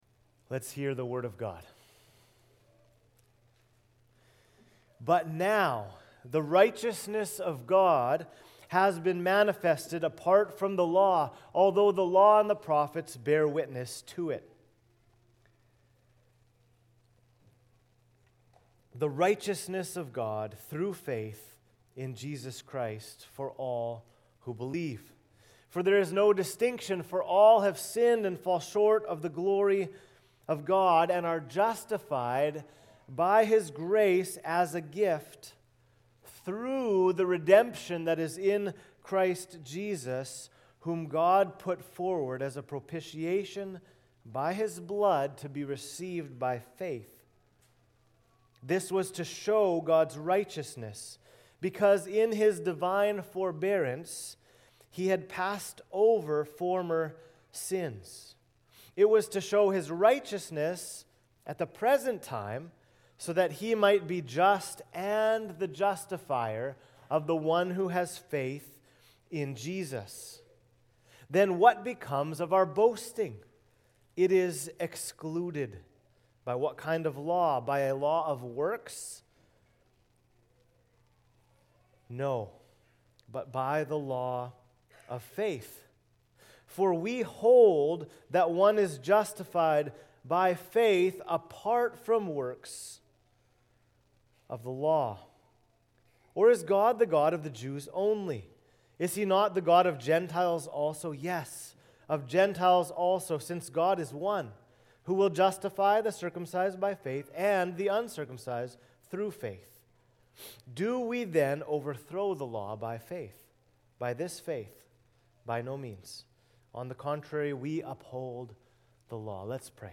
Sermons | Cornerstone Bible Church